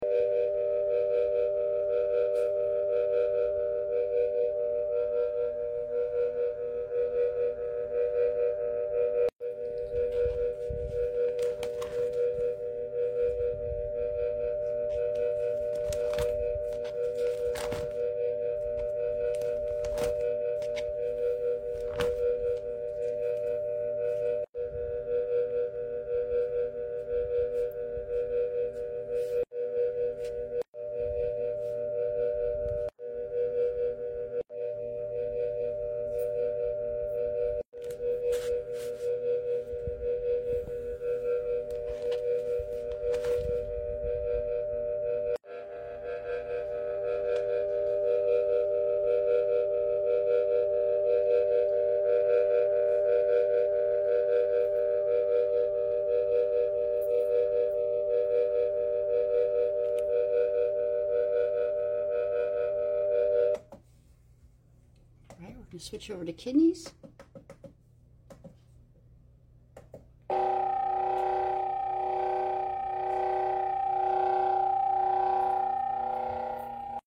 listen to ancient healing frequencies sound effects free download